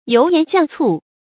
油鹽醬醋 注音： ㄧㄡˊ ㄧㄢˊ ㄐㄧㄤˋ ㄘㄨˋ 讀音讀法： 意思解釋： ①泛指烹調佐料。